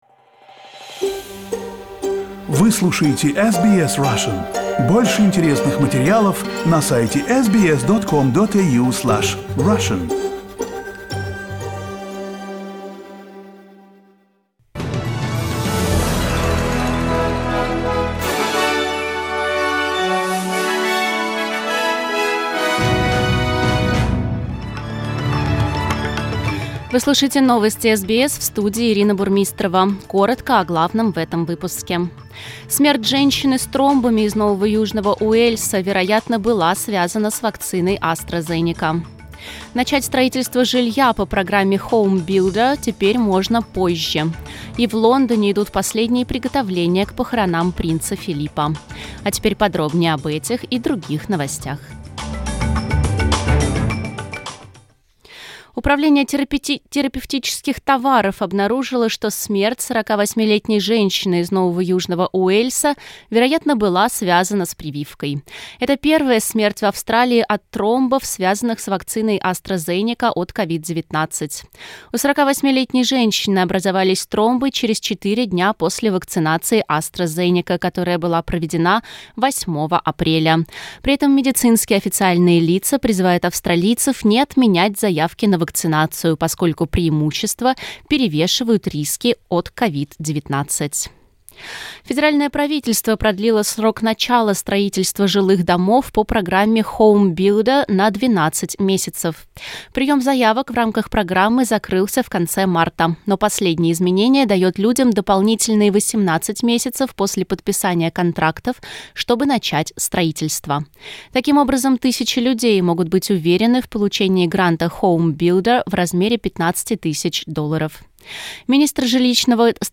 News bulletin in Russian - 17.04
Listen to the latest news headlines in Australia from SBS Russian radio.